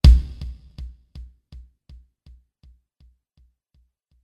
バスドラム01は、楽器のバスドラムが打撃されたときの特徴的な音を表現したものです。
この音は低く、力強く響く特徴があります。